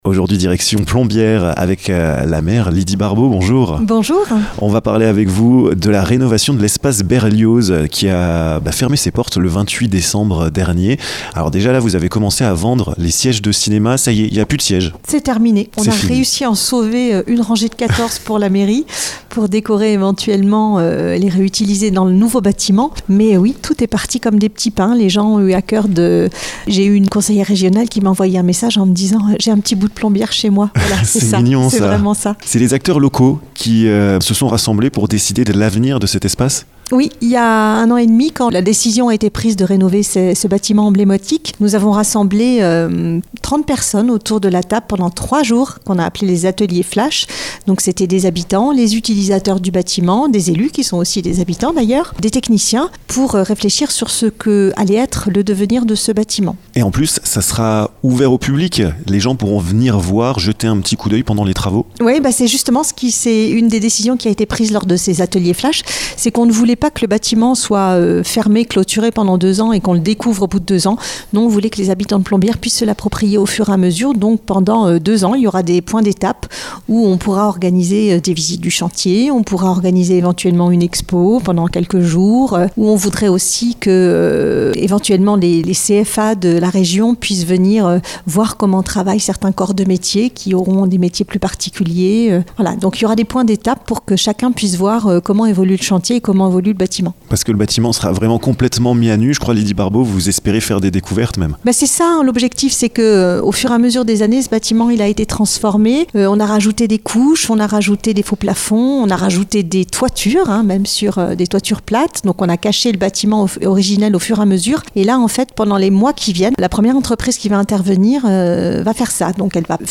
La maire de Plombières-les-Bains, Lydie Barbaux, nous à dernièrement reçu dans son bureau. Avec elle, nous avons évoqué la rénovation de l'espace Berlioz jusqu'en 2025.